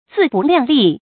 注音：ㄗㄧˋ ㄅㄨˋ ㄌㄧㄤˋ ㄌㄧˋ
自不量力的讀法